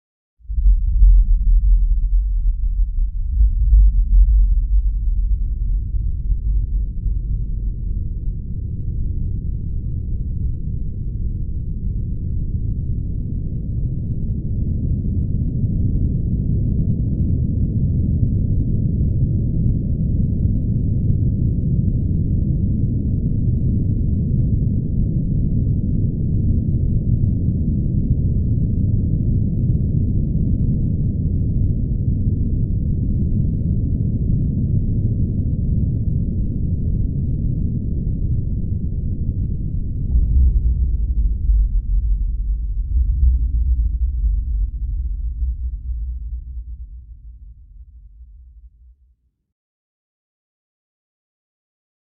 SFX地震震动越来越强的声音音效下载
SFX音效